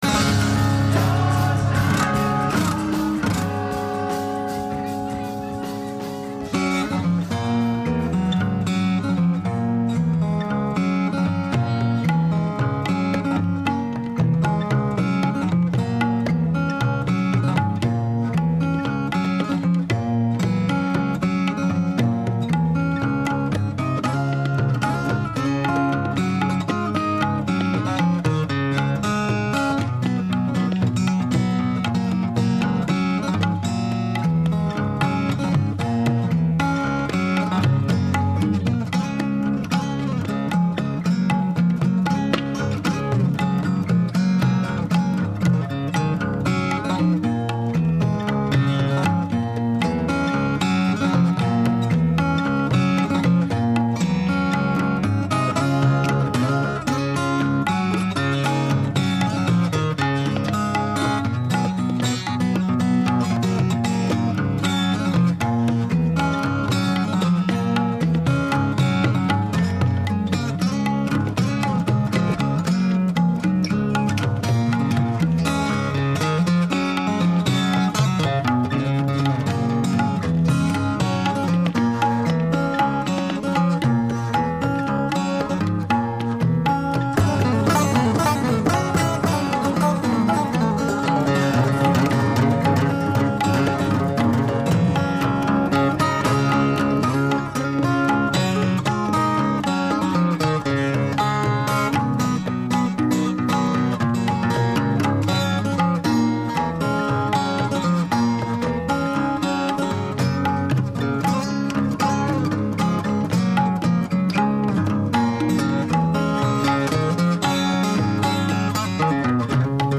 Recorded in October 1968 in Olympic Studios (Barnes).
acoustic guitars
tabla
Intro     Guitar harmonics, tuning.
Approximately 8-beat recurring melodic figure.
Set in higher register
C     Set in lower register
D     Fast runs in odd-numbered beat lengths.
A     Ends abruptly.